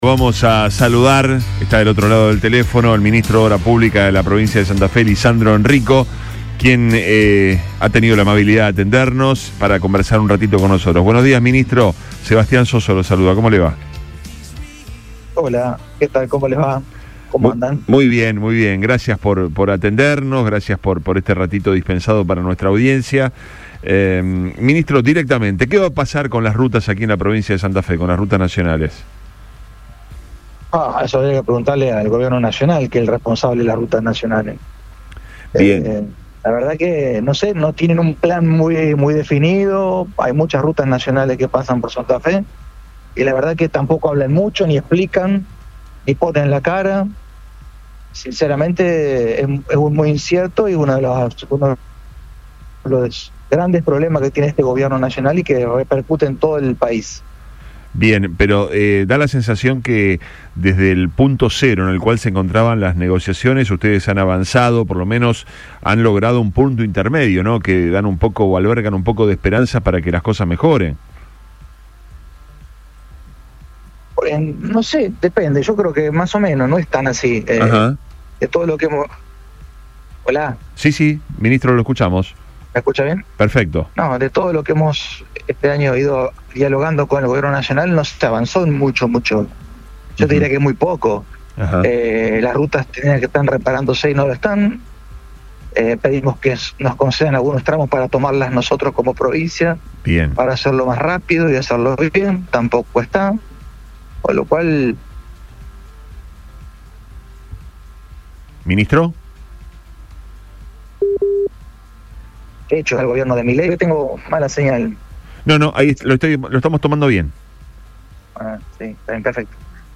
En medio de discusiones entre el Gobierno nacional y la provincia de Santa Fe por el estado de las rutas, el Ministro de Obras Públicas, Lisandro Enrico, habló en Rio 96.9 y apuntó contra la gestión de Javier Milei.